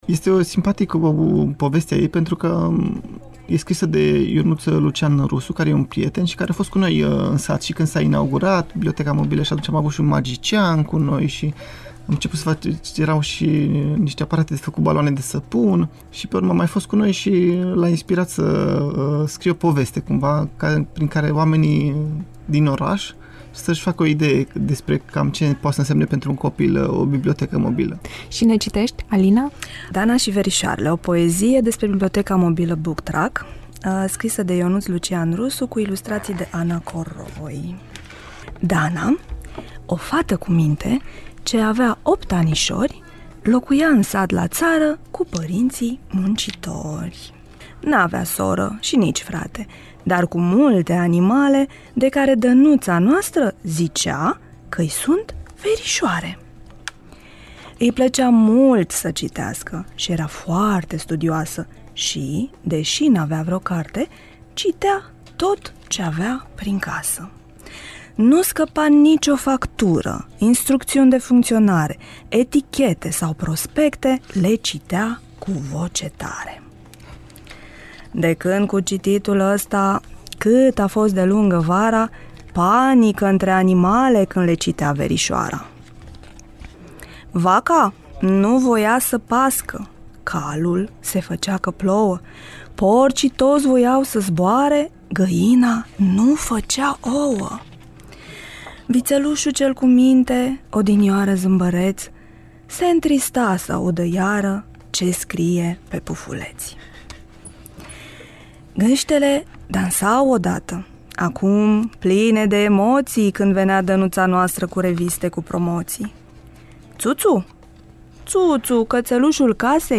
au fost în studioul nostru și au povestit despre Dana și verișoarele ei.